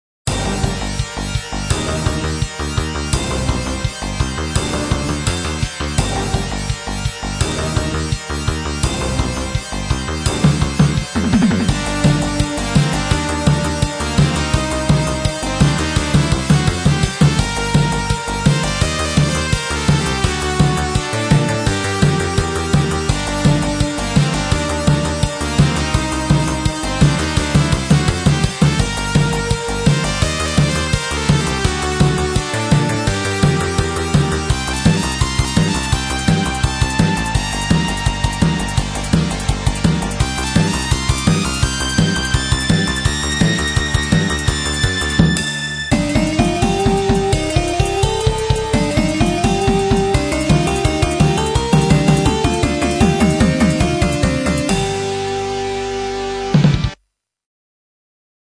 PC-88VA2のサウンドボードIIの音源をそのまま録音した曲集です。